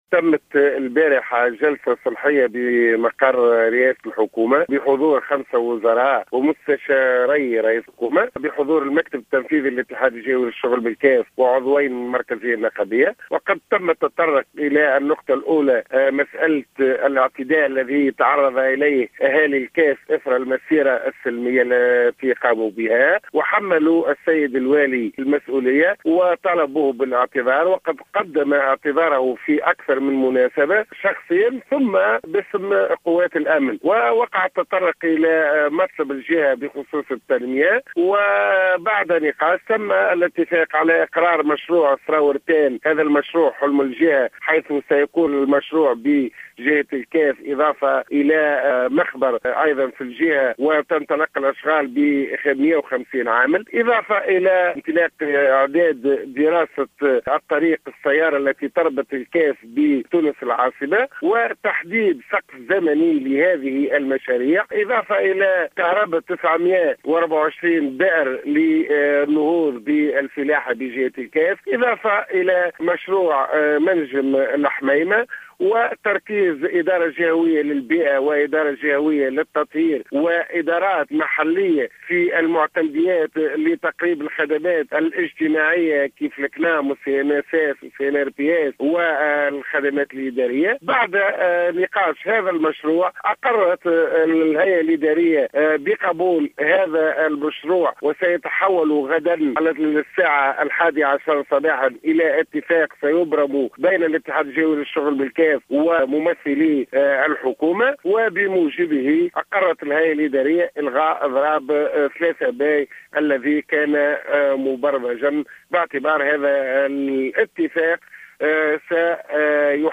في تصريح للجوهرة أف أم اليوم الأحد 1 ماي 2016 أنه تقرر إلغاء الإضراب العام الذي كان مقررا تنفيذه في الجهة في الثالث من ماي الجاري.